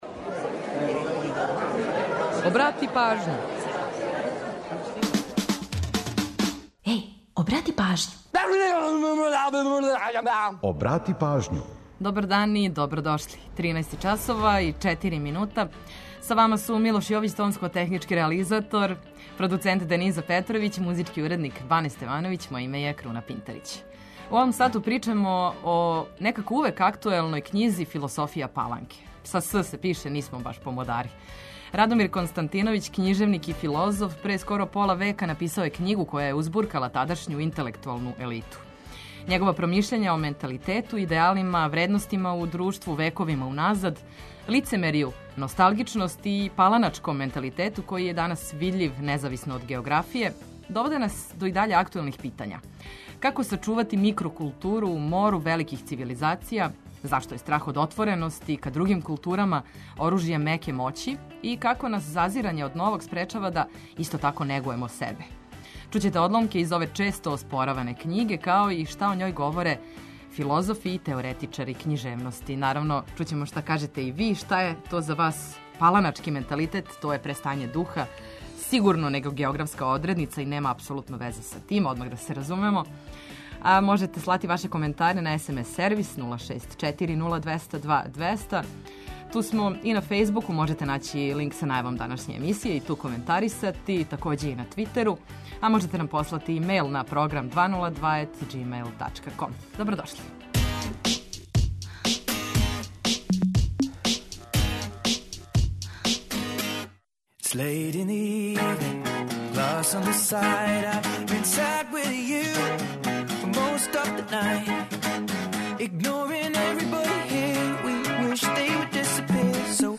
Чућете одломке из ове често оспораване књиге, као и шта о њој говоре филозофи и теоретичари књижевности.